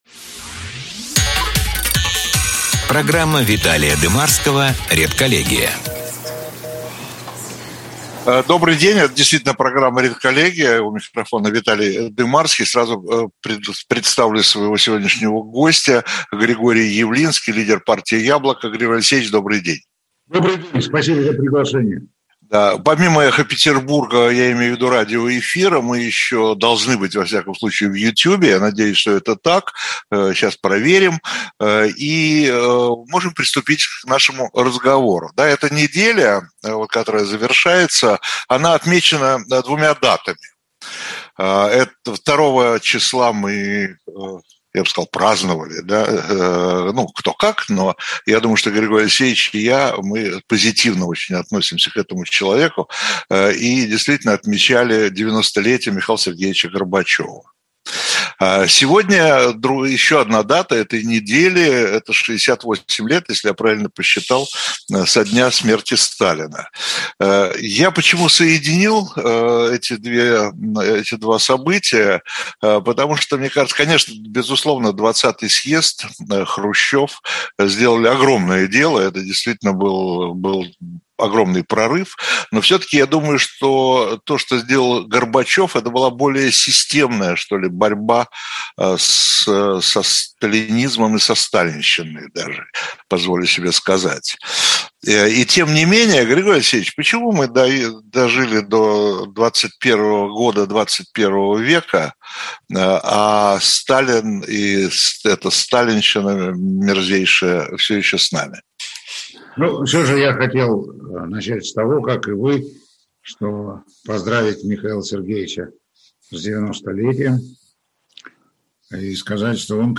Редколлегия - Григорий Явлинский - Интервью - 2021-03-05